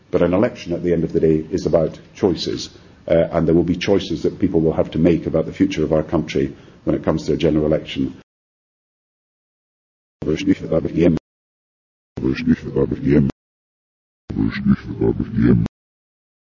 Press Conference with British PM Gordon Brown